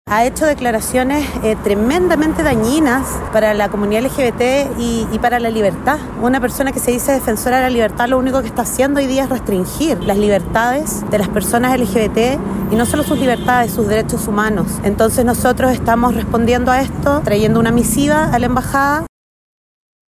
movilh-protesta-contra-milei.mp3